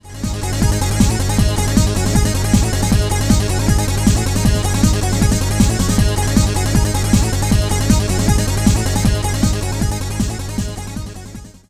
改造SPI基板から収録したBGM
もう、全然違います。ちゃんとした音が鳴っています。